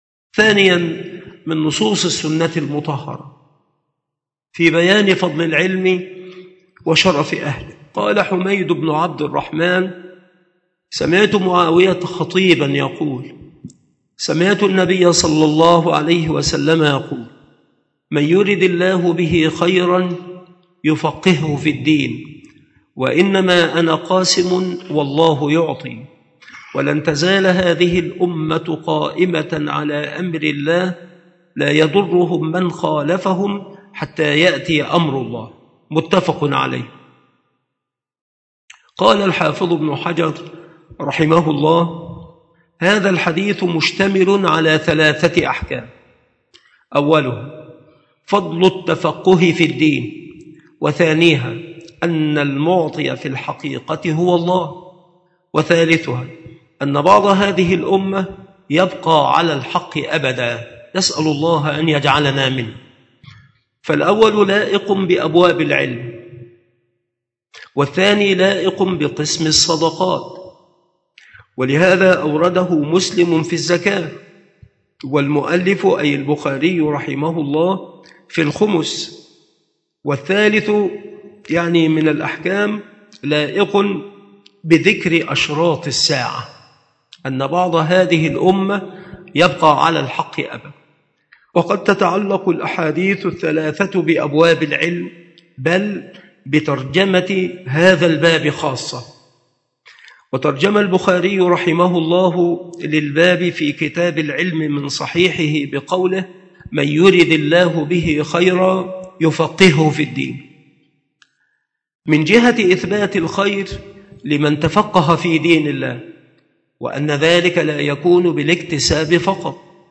مكان إلقاء هذه المحاضرة بالمسجد الشرقي بسبك الأحد - أشمون - محافظة المنوفية - مصر